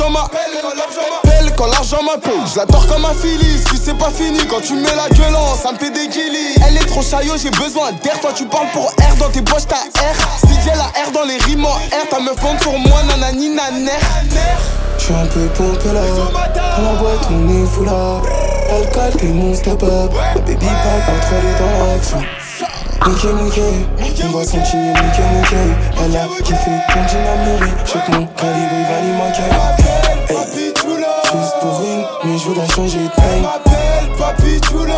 Rap Hip-Hop
Жанр: Хип-Хоп / Рэп